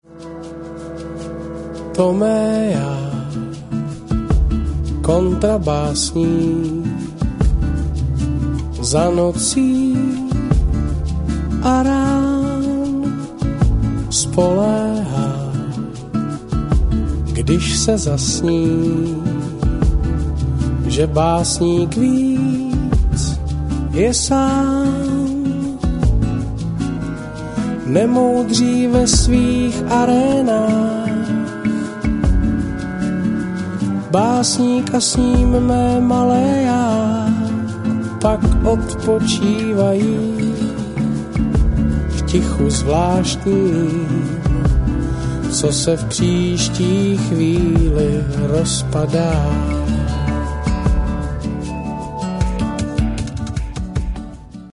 Singer of the famous Czech funky band J.A.R. .
with jazz influence